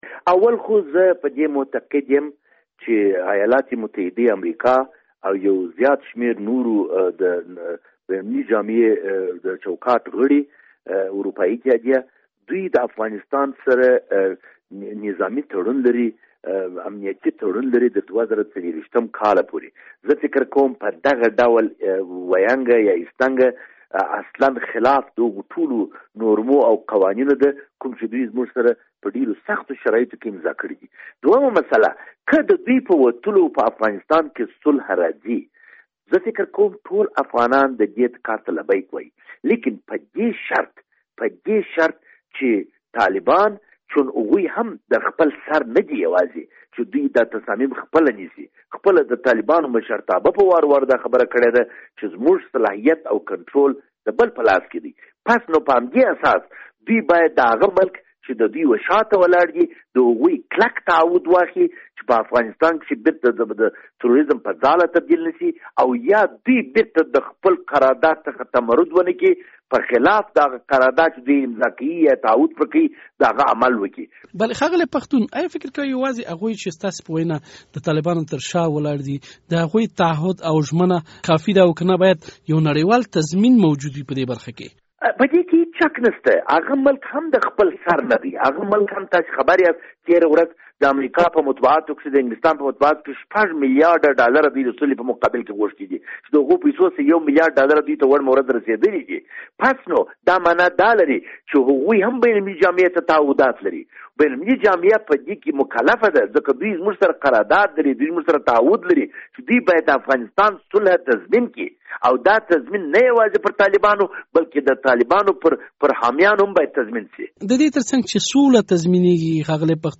مرکه
له خالد پښتون سره مرکه